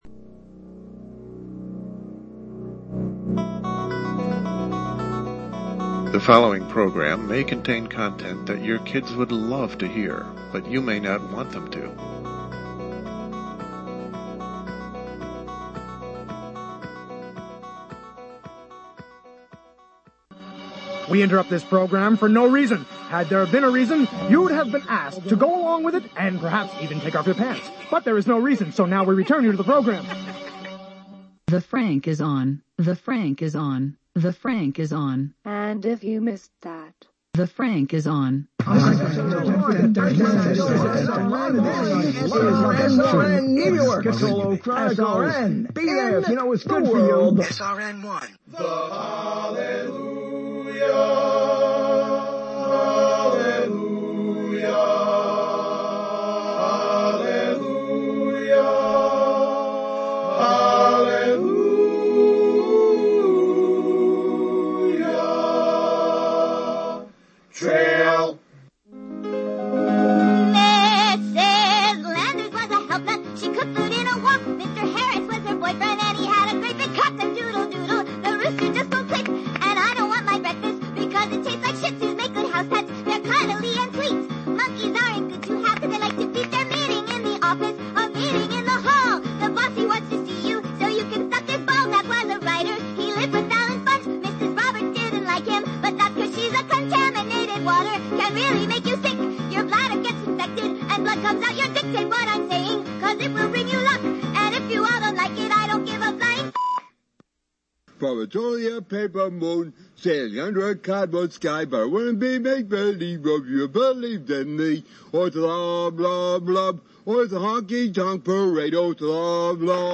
LIVE, Thursday, Nov. 26 at 9 p.m. Eastern.
He sits by the old ivories and wanders over the worn 88s, singing torch tunes and other songs he turns into torch tunes.
It’s all on the original Internet radio talk show, now in its 20th season.